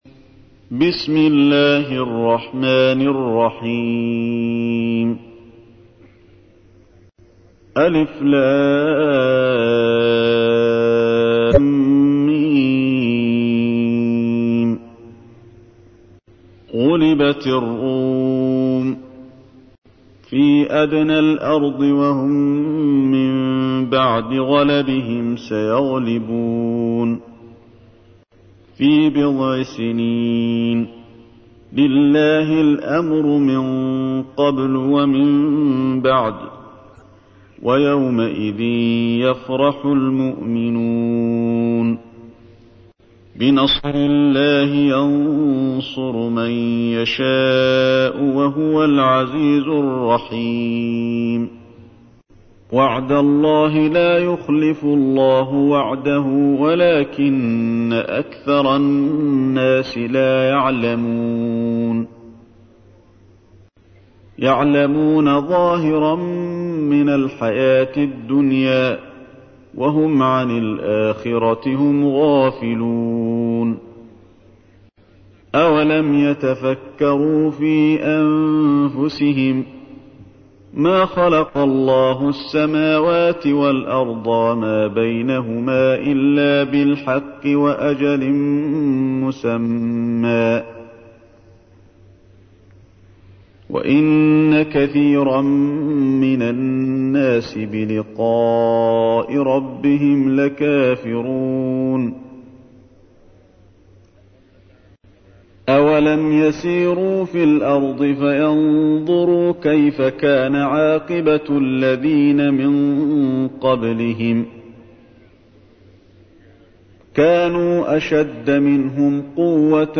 تحميل : 30. سورة الروم / القارئ علي الحذيفي / القرآن الكريم / موقع يا حسين